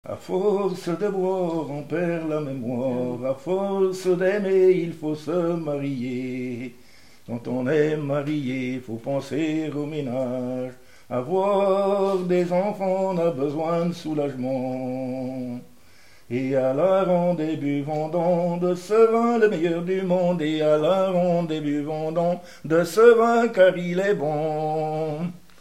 circonstance : bachique
Pièce musicale inédite